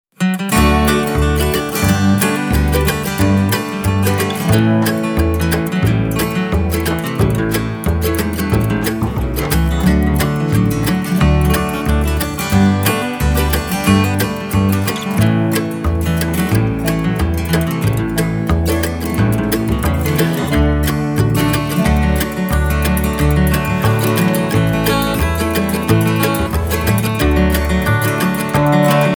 An album of Swedish Bluegrass music.